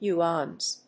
/ˈjuːʌnz(英国英語)/